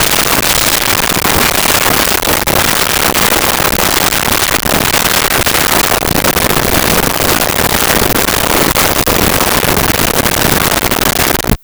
Cat Purring
Cat Purring.wav